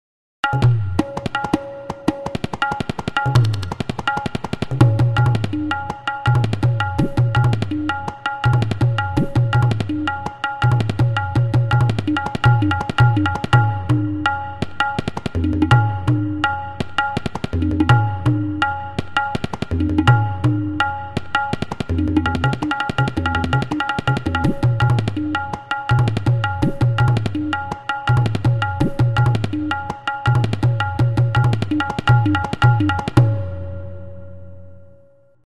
The tabla is a pair of two drums.
The smaller higher pitched drum (shown on the right) is known as the dayan, which is made of a hard wood like shisham, rosewood, or teak.
AUDIO CLIP: Tabla
tabla.mp3